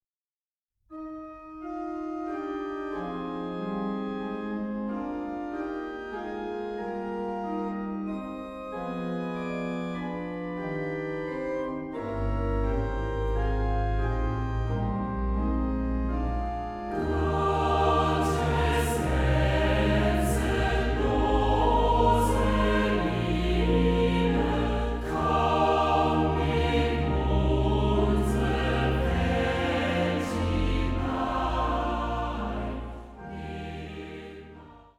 Orgel, Akkordeon